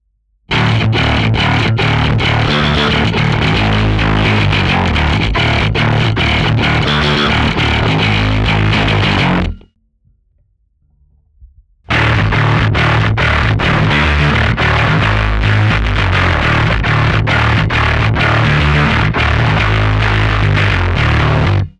Der Royale TS (voller Name »Bassdrive Royale TS«) ist ein Overdrive, der aber auch ziemlich amtlich in die Verzerrung geht. Im Anhang ein Mini Demo, das ich gestern Nacht über den RTS direkt ins Interface gespielt habe.
Teil eins ist der MEC, Teil zwei der Seymour - jeweils ohne Schnickschnack direkt in den RTS.
Kein weiterer EQ oder sonst irgendwas, außer leichtem Noisegate via Audacity in den Spielpausen. Für meinen Geschmack schon eine Schippe zu viel Gain um im Mix Sinn zu machen, aber solo auf den Kopfhörern hat es Bock gemacht.